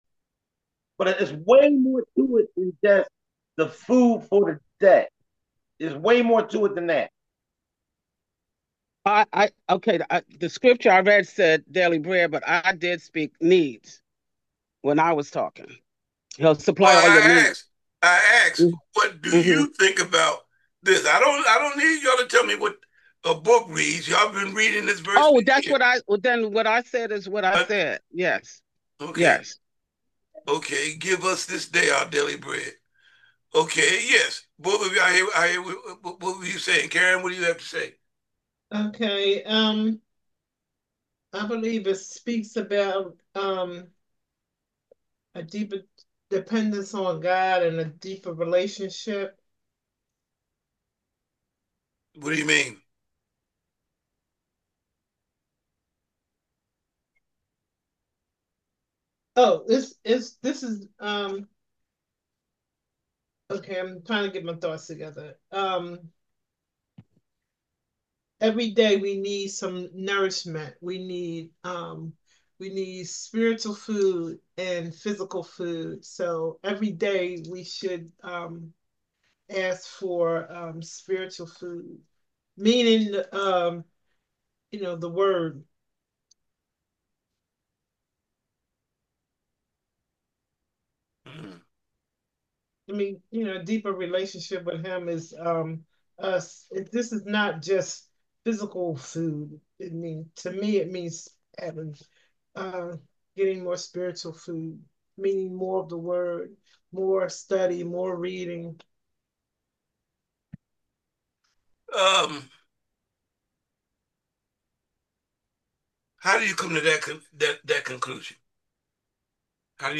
Give Us This Day: Wednesday Night Bible Study - St James Missionary Baptist Church